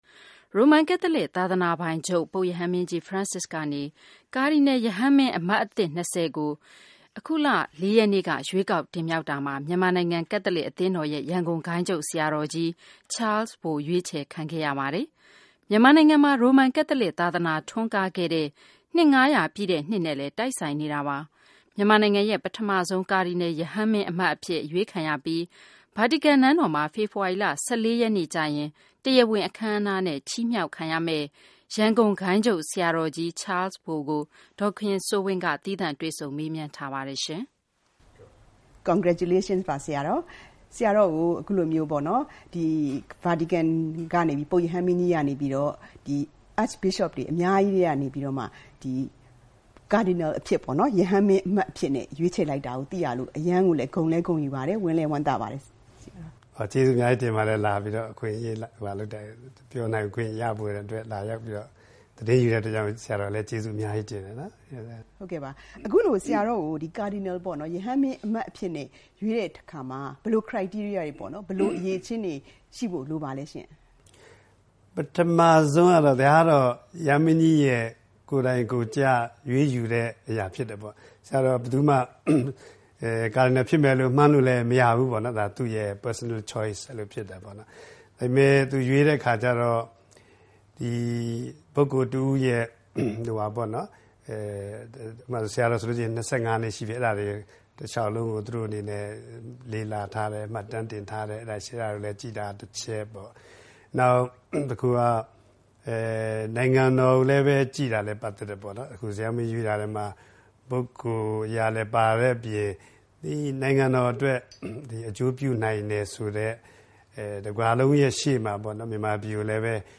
Cardinal ဘုန်းတော်ကြီး ချားလ်မောင်ဘိုနဲ့ ဗွီအိုအေ သီးသန့်တွေ့ဆုံမေးမြန်းမှု